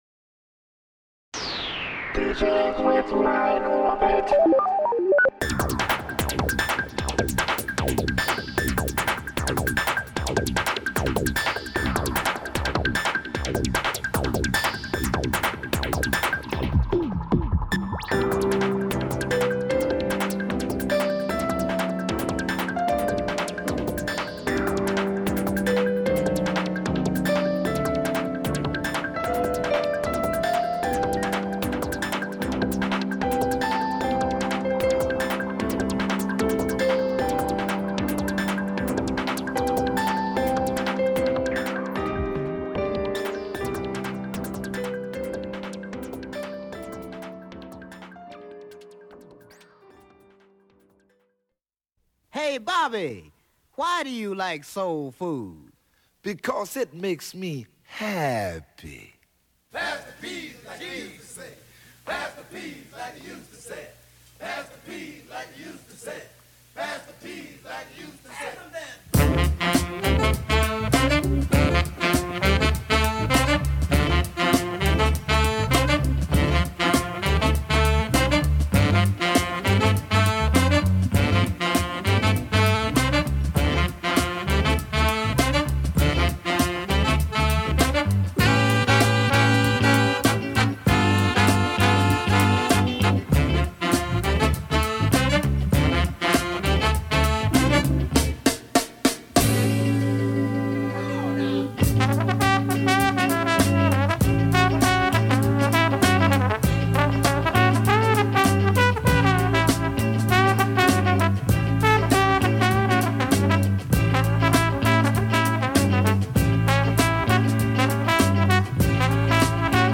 Keep on listening the funk music, keep on taking a rest.